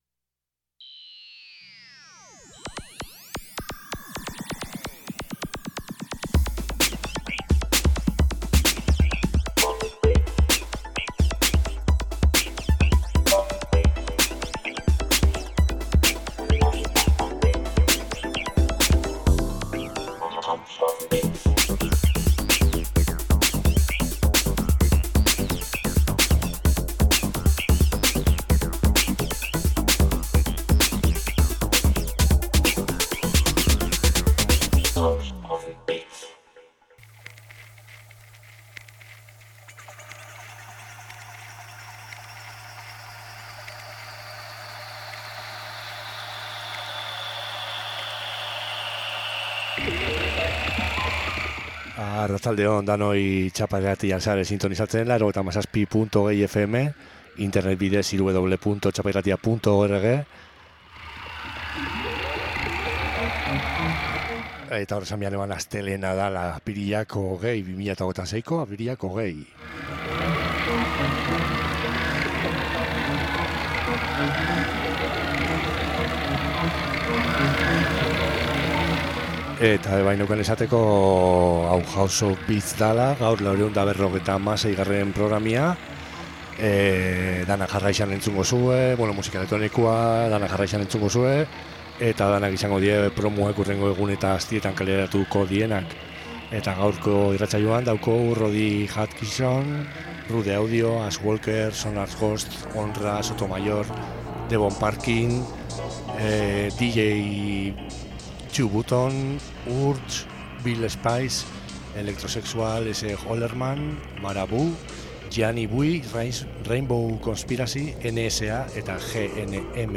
Aste hontako irratsaioan… / This weeks radioshow..